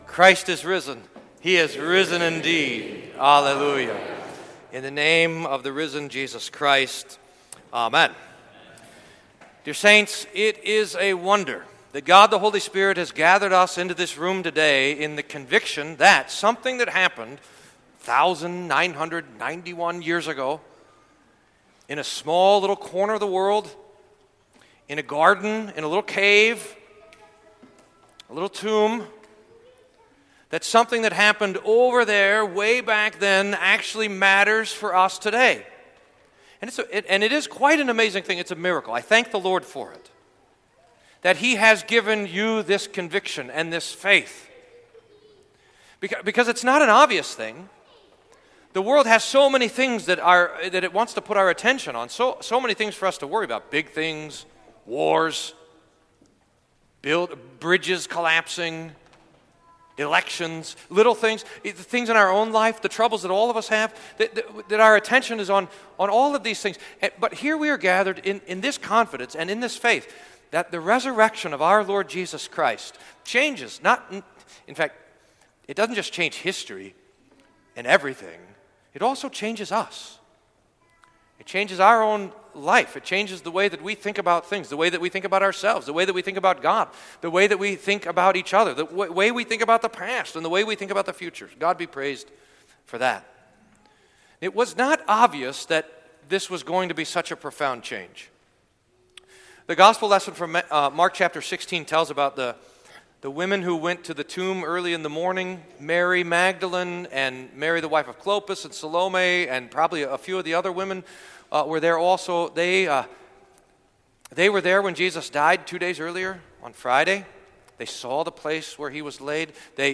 Sermon for Easter Sunday